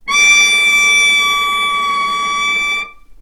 vc-D6-ff.AIF